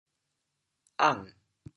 How to say the words 晏 in Teochew？
ang3.mp3